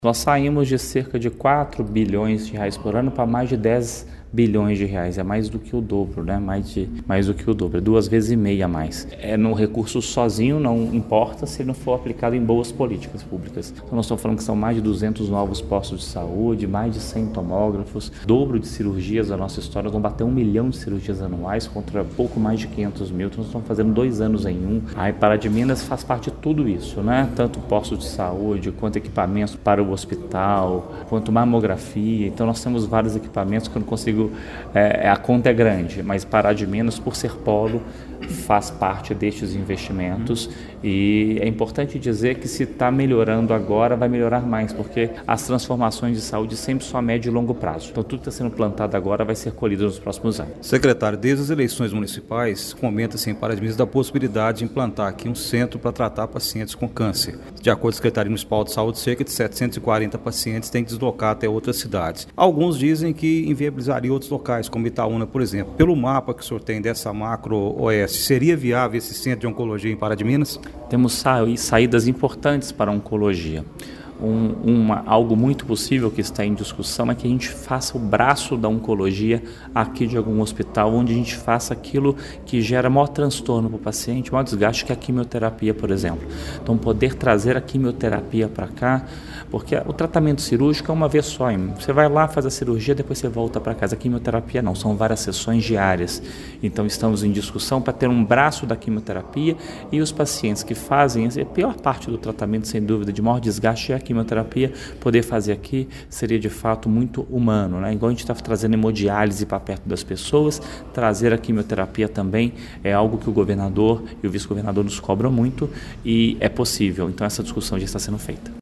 Questionado pela reportagem do Portal GRNEWS, sobre a viabilidade de implantar esse centro em Pará de Minas, o secretário de Estado de Saúde, Fábio Baccheretti, respondeu é viável sim, possível e já existem tratativas nesse sentido para atender os pacientes com câncer em Pará de Minas. Ele também falou sobre os investimentos que estão sendo feitos na saúde pública em Minas Gerais: